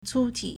粗体 (粗體) cūtǐ
cu1ti3.mp3